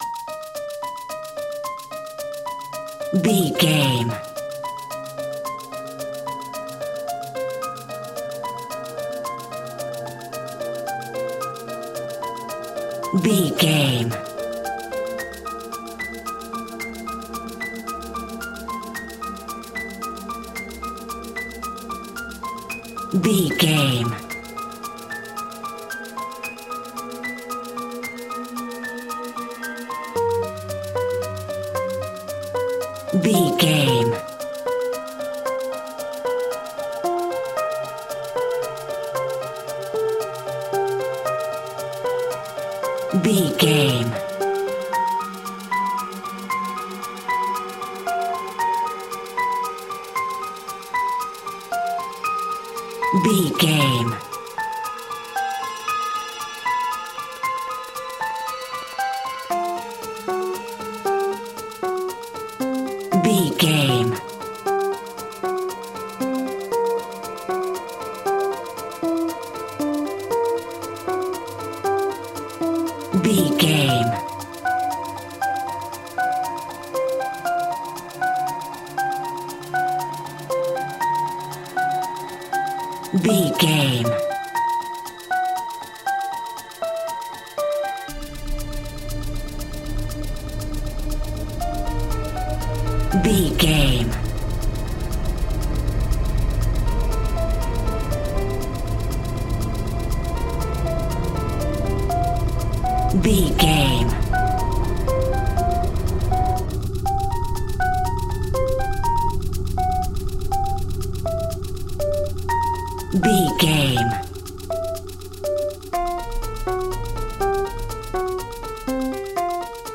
In-crescendo
Aeolian/Minor
E♭
tension
ominous
dark
haunting
eerie
piano
synthesiser
percussion
drums
ambience
pads